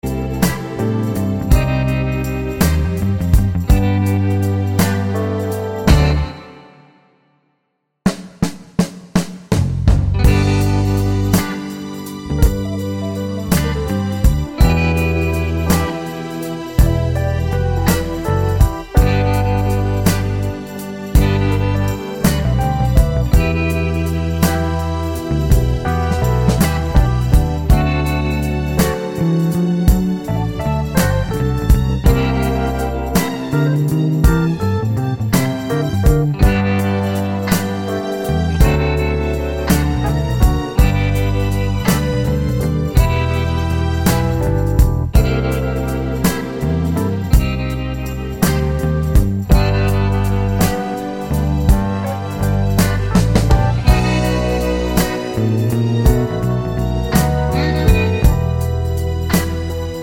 no Backing Vocals Soul / Motown 4:00 Buy £1.50